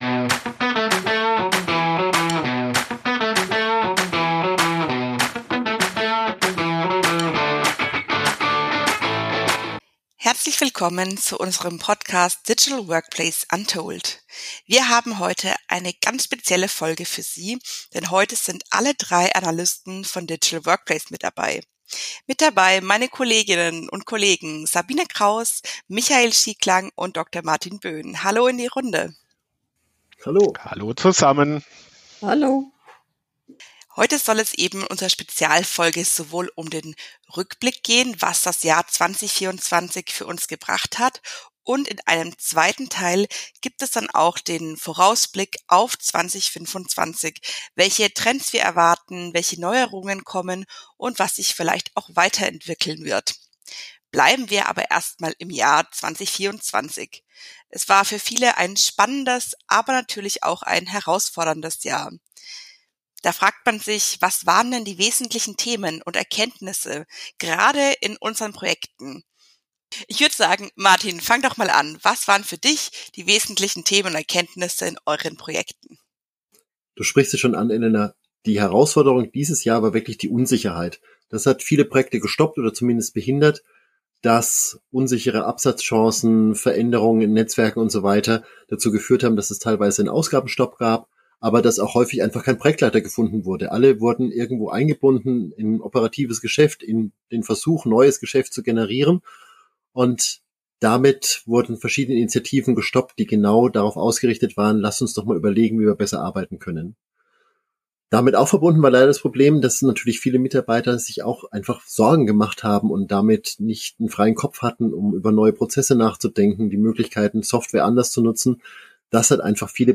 Was ist alles 2024 im Bereich Digital Workplace passiert? Unsere drei Analysten im Gespräch über die Herausforderungen, Unsicherheiten und Fachkräftemangel aber auch wie KI den Markt beeinflusst hat und was sich für ECM und CRM getan hat.